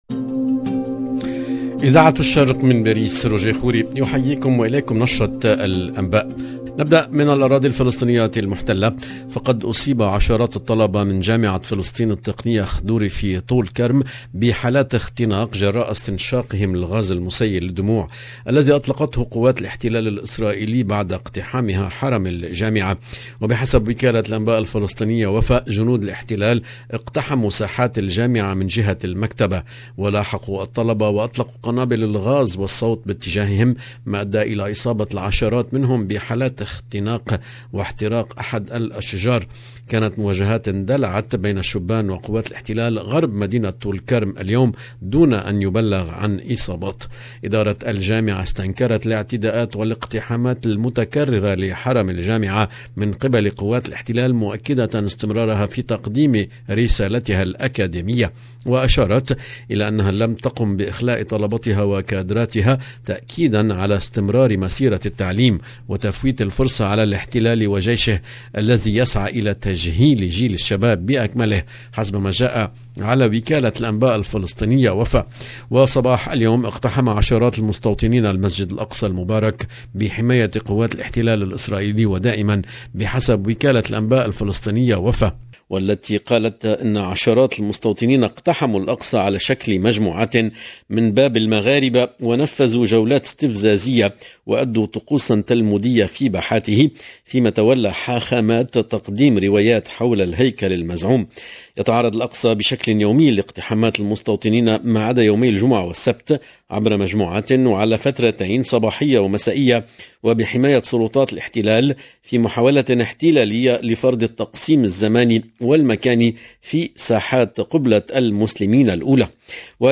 LE JOURNAL DU SOIR EN LANGUE ARABE DU 18/05/22